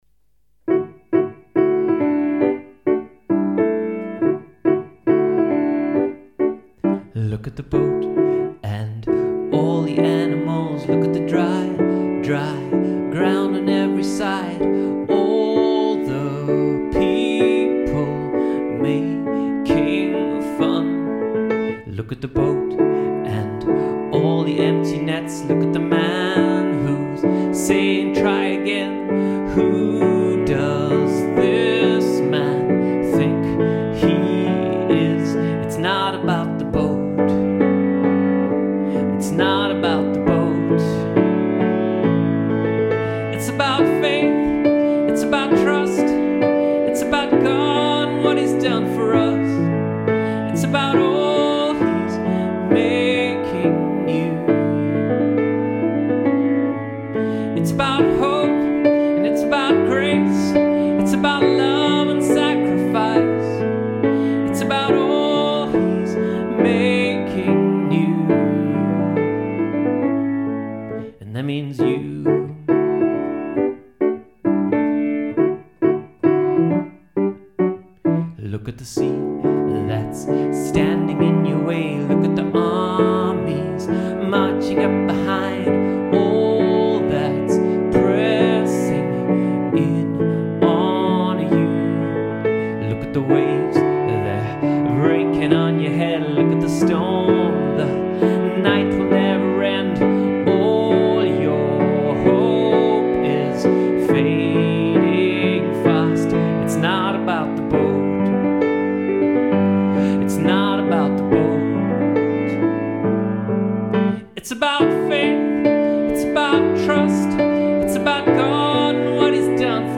It's more a fun family worship song than anything too serious, but it looks at what some of the great characters of the bible were facing before they were obedient and became "heroes of faith".